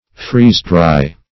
Freeze-dry \Freeze"-dry`\ (fr[=e]z"-dr[imac]`), v. t. [imp. & p.